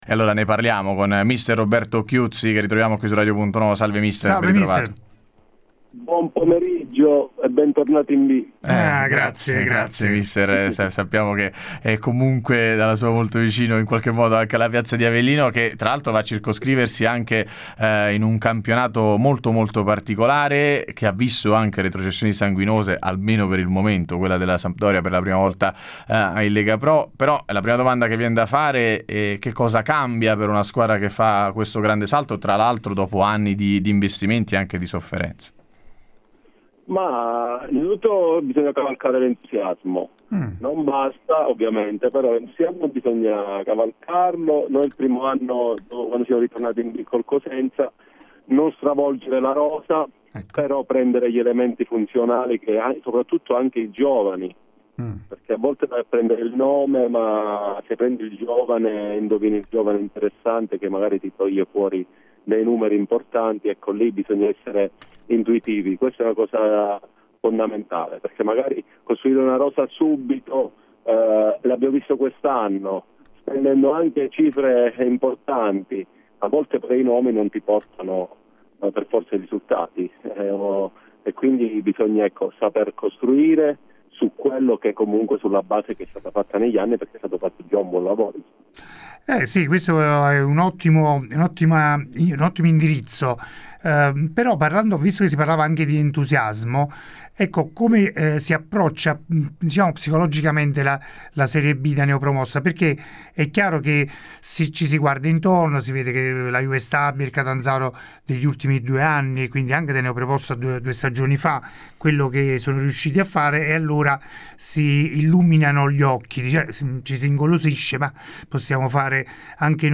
Ospite de Il Pomeriggio da Supereroi di Radio Punto Nuovo